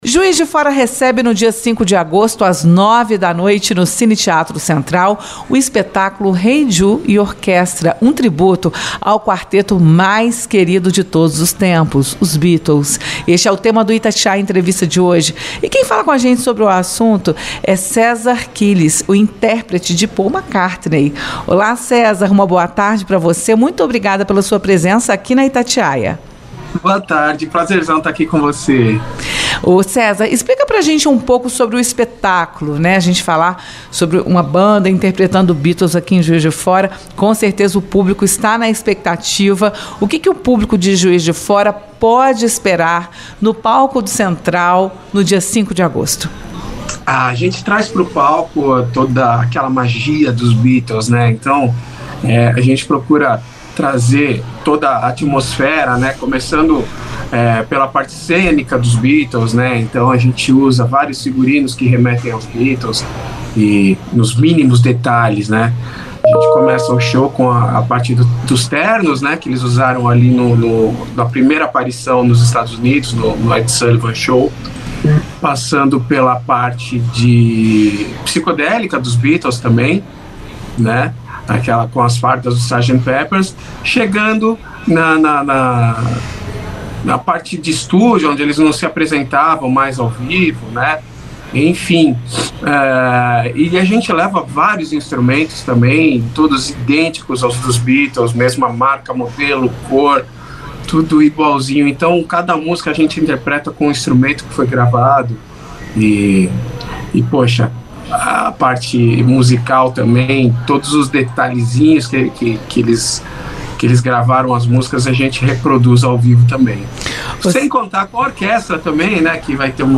Juiz de Fora recebe, no dia 05 de agosto, às 21h, no Cine Theatro Central, o espetáculo “Hey Jude & Orquestra”, um tributo ao quarteto mais querido de todos os tempos: os Beatles. Este é o tema do Itatiaia Entrevista.